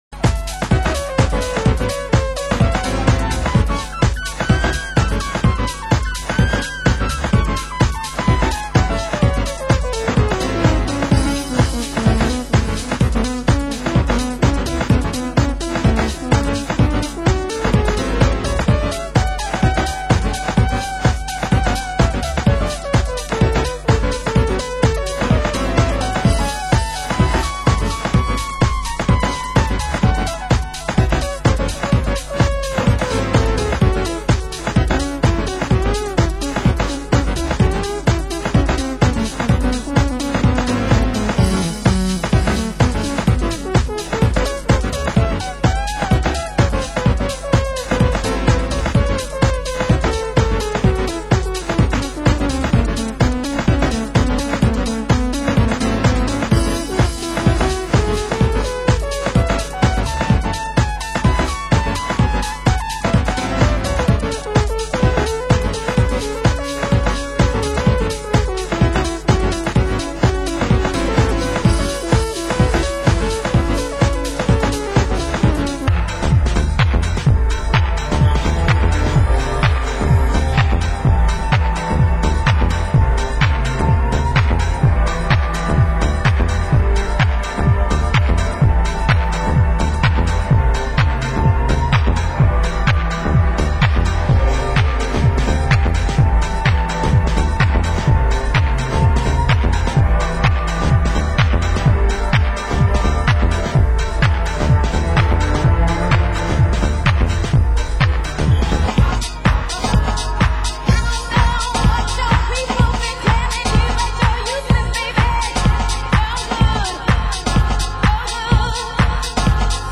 Format: Vinyl 12 Inch
Genre: Deep House